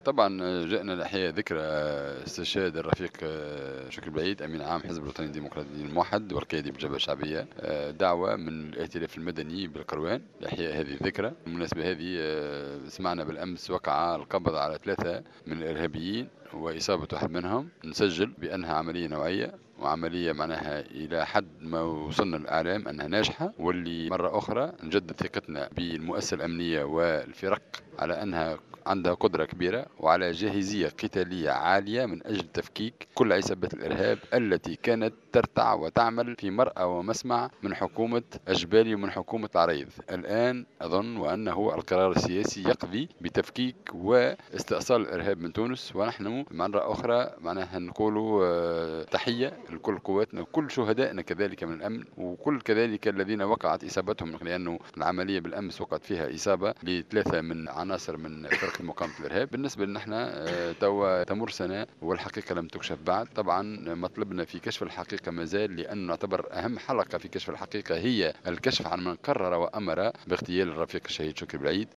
عبر القيادي في الجبهة الشعبية المنجي الرحوي في تصريح لمراسل جوهرة "اف ام" اليوم خلال لقاء نظمه الائتلاف المدني بالقيروان لإحياء ذكرى اغتيال الشهيد شكري بلعيد عن تجديد ثقته بالمؤسسة الأمنية بعد تمكنها مساء أمس من القبض على مجموعة ارهابية.